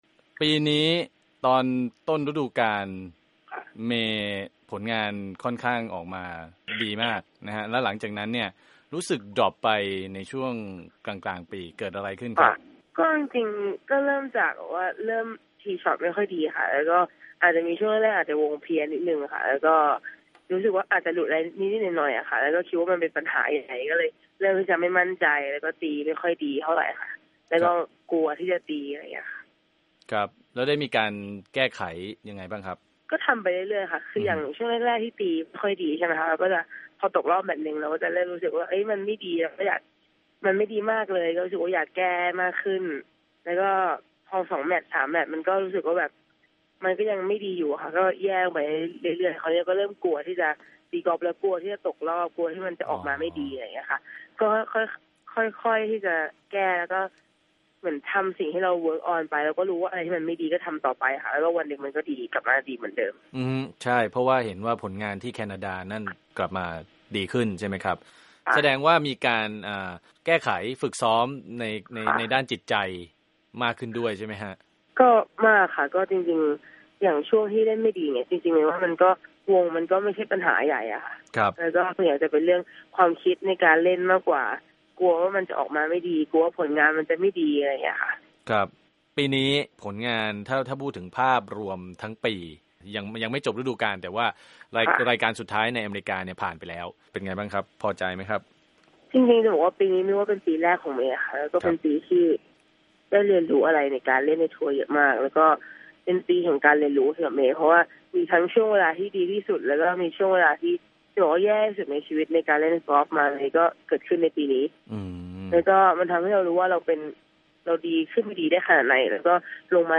Interview Pro Mae - Ariya Jutanugal